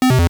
pokegear_off.wav